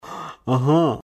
Звуки согласия
Все файлы записаны четко, без фоновых шумов.
Ага мужское